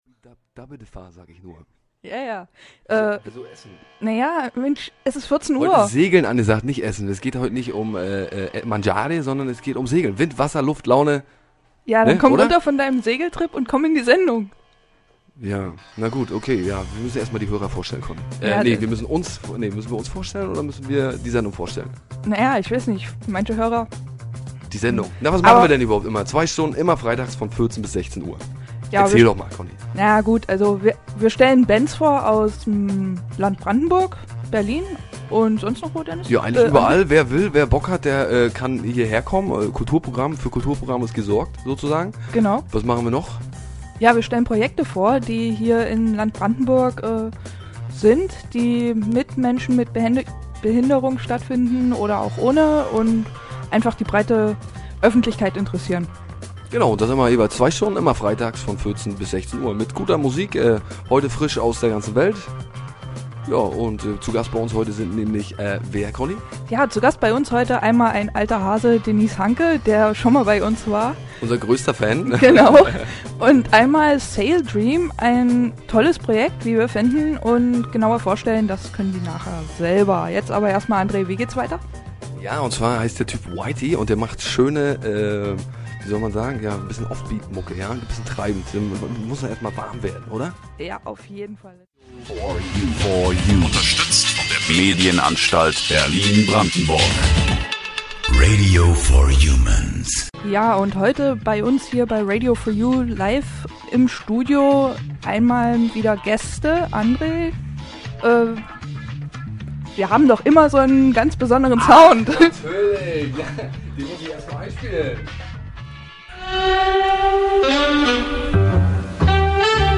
Radio4u: Radiosendung zum Projekt Saildream1.deMP3